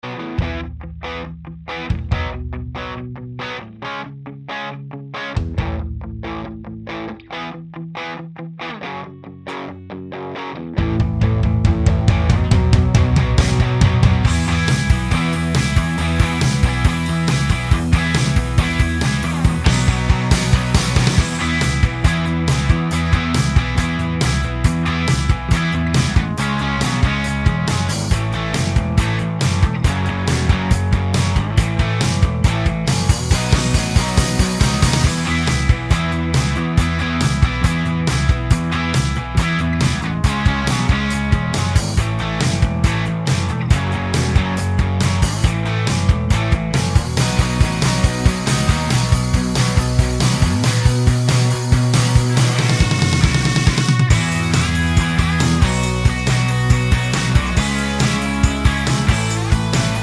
o Backing Vocals) Finnish 3:20 Buy £1.50